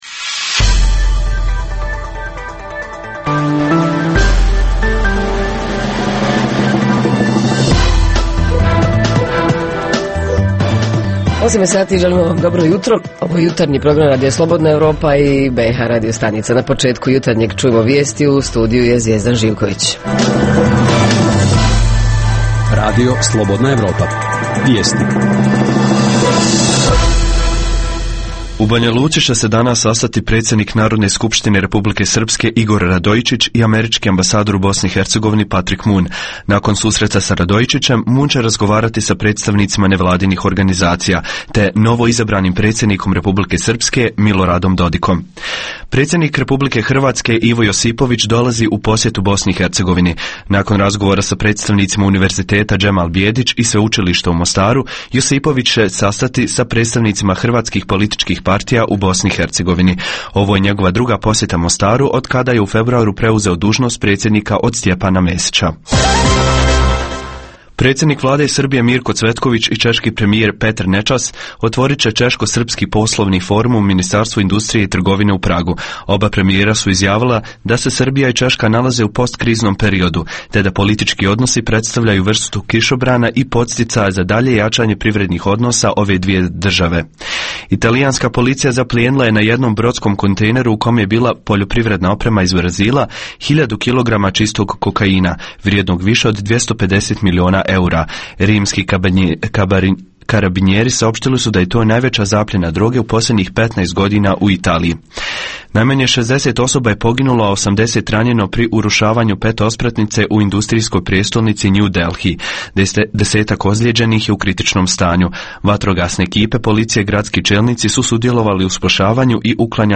Redovna rubrika Radija 27 utorkom je "Svijet interneta". Redovni sadržaji jutarnjeg programa za BiH su i vijesti i muzika.